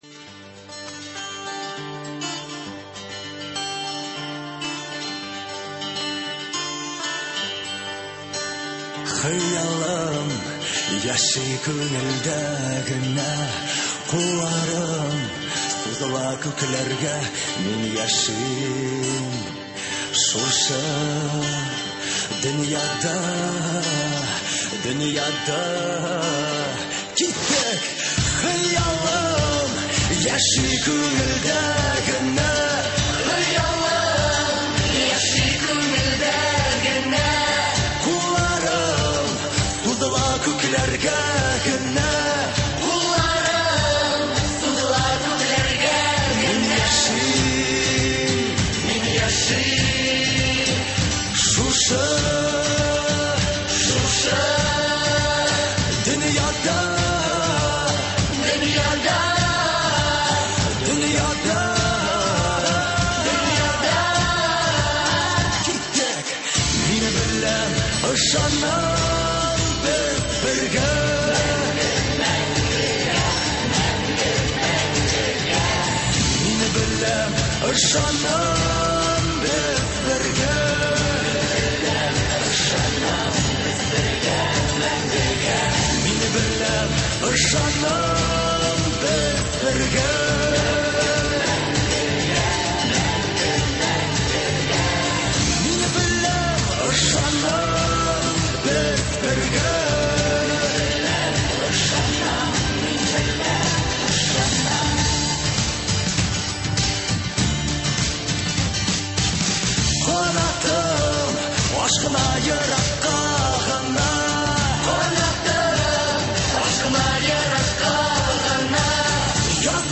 Бүген без студиябезгә озак еллар балаларның җәйге ялын оештыру һәм алай гына да түгел, аларны файдалы белемнәр белән тәэмин итүче “Сәләт” Яшьләр Үзәге вәкилләрен чакырдык.